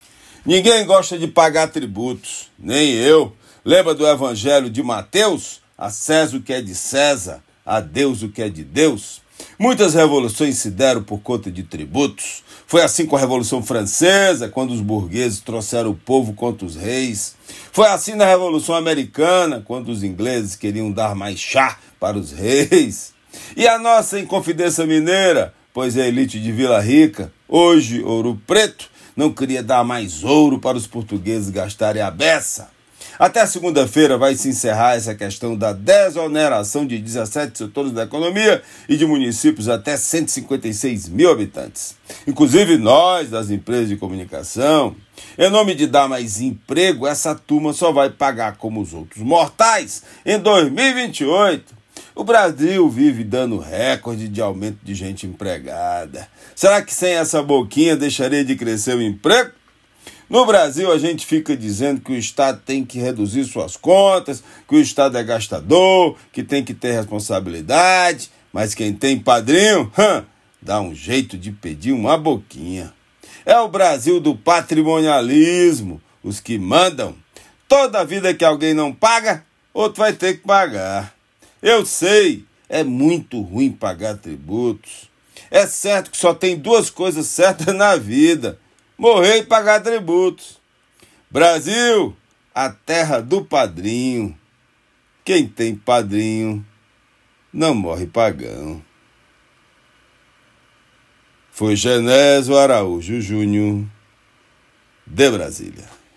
Comentário desta sexta-feira
direto de Brasília.